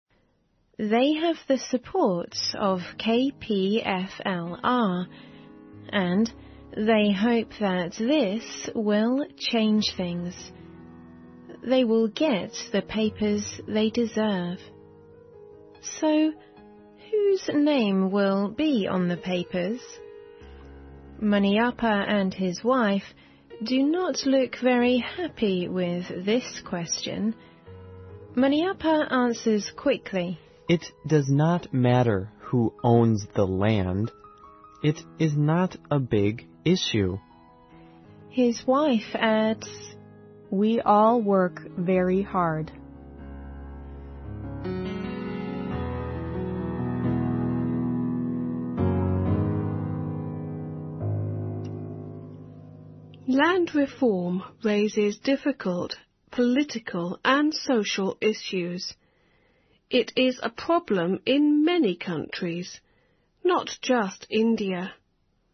环球慢速英语 第547期:女性和土地改革(7)